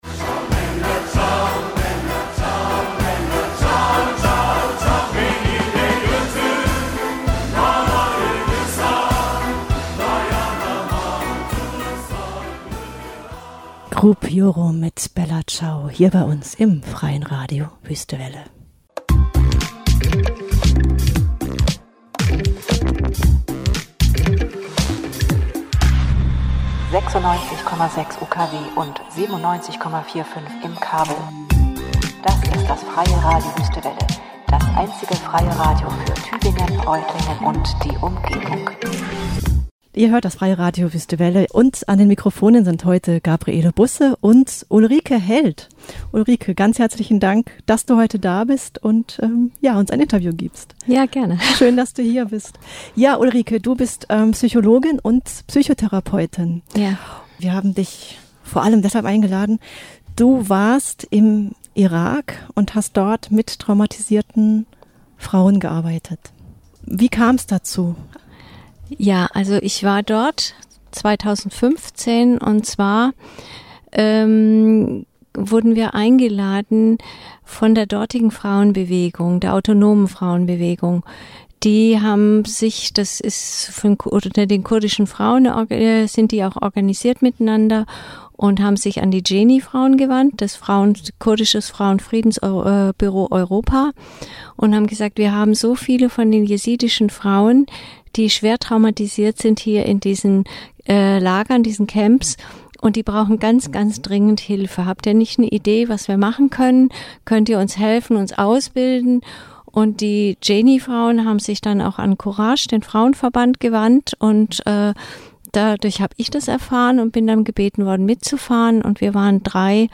Im Interview berichtet sie von ihrer Reise und ihrer Arbeit im Lager von Sulaimaniyya - und betont, dass nicht nur sie den Frauen dort geholfen habe, sondern dass sie selbst unheimlich viel von ihnen gelernt habe.